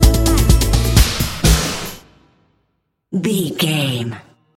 Epic / Action
Aeolian/Minor
Fast
drum machine
synthesiser
strings